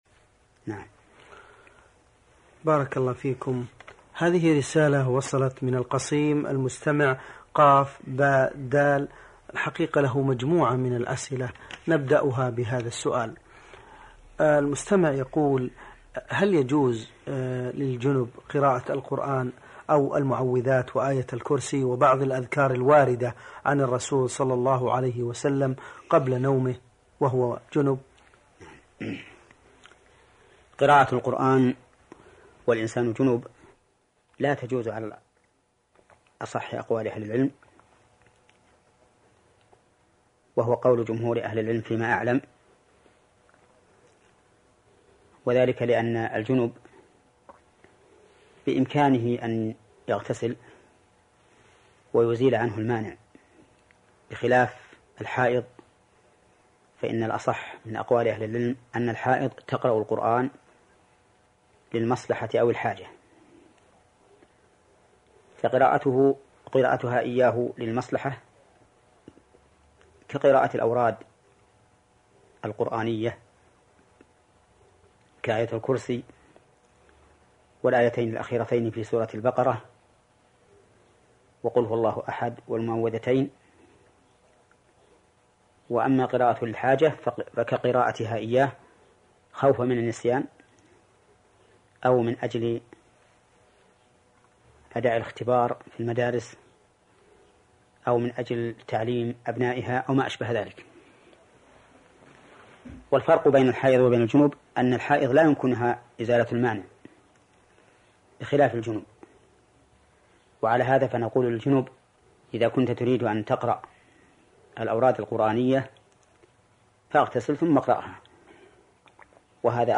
المصدر: سلسلة فتاوى نور على الدرب > الشريط رقم [177]